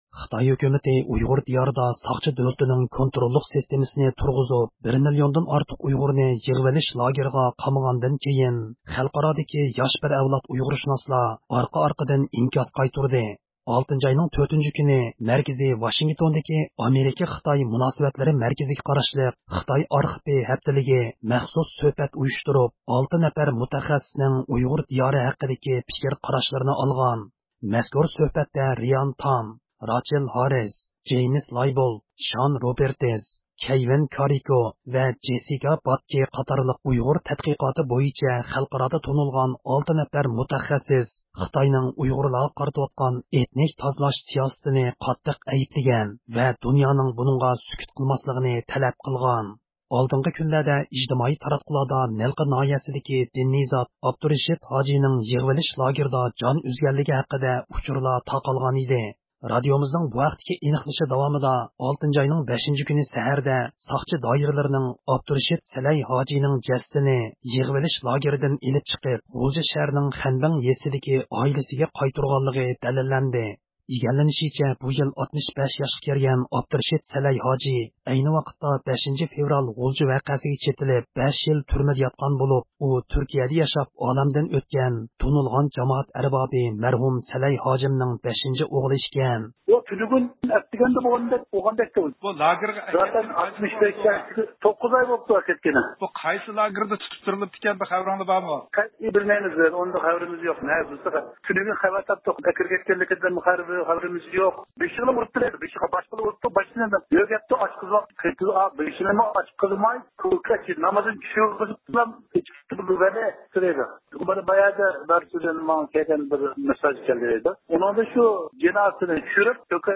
ھەپتىلىك خەۋەرلەر (2-ئىيۇندىن 8-ئىيۇنغىچە) – ئۇيغۇر مىللى ھەركىتى